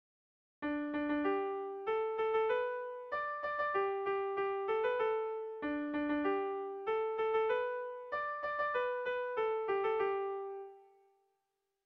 Irrizkoa
Lau puntuko berdina, 8 silabaz
AB1AB2